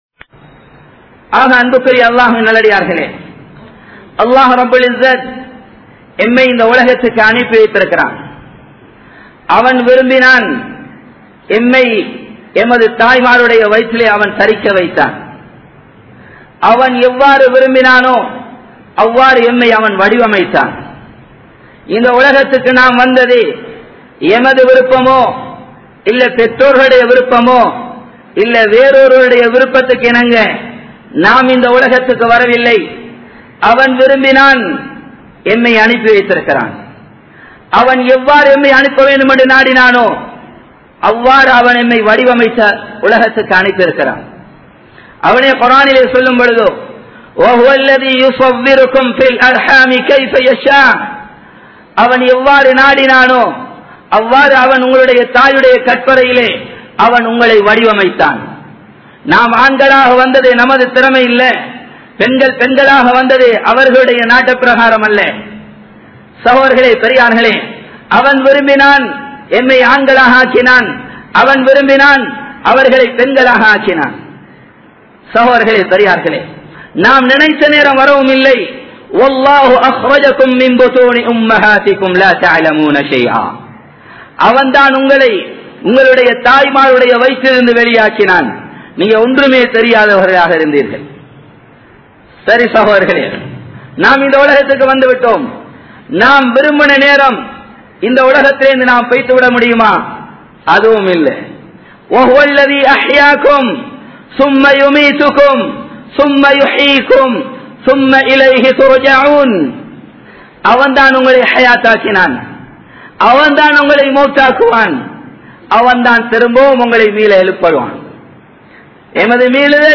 Puram Peasatheerhal (புறம் பேசாதீர்கள்) | Audio Bayans | All Ceylon Muslim Youth Community | Addalaichenai
Colombo 11, Samman Kottu Jumua Masjith (Red Masjith)